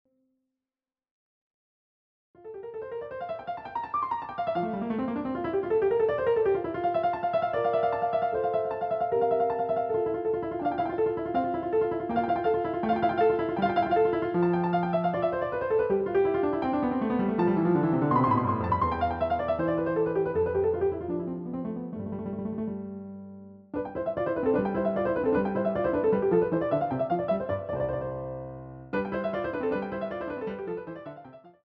CD quality digital audio
using the stereo sa1mpled sound of a Yamaha Grand Piano.